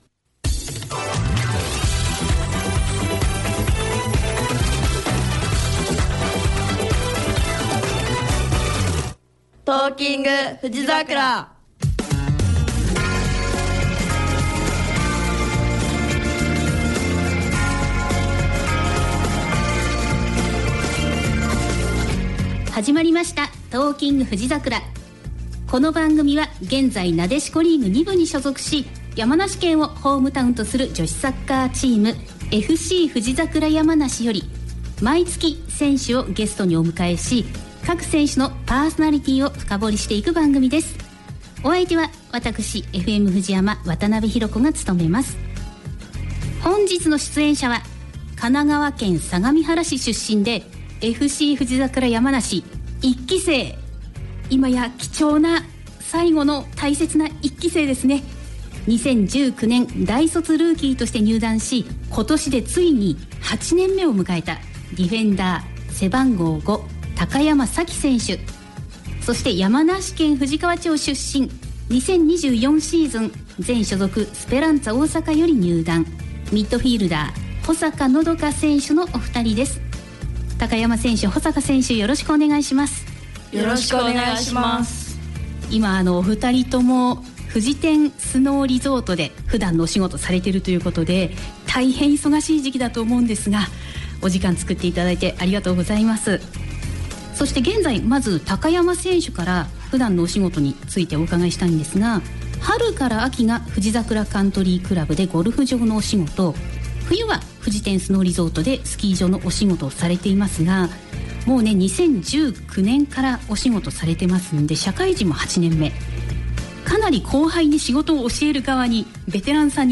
お二人の空気感がとても素敵です。
※版権の都合上ラスト曲はカットしています。